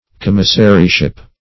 Search Result for " commissaryship" : The Collaborative International Dictionary of English v.0.48: Commissaryship \Com"mis*sa*ry*ship\, n. The office or employment of a commissary.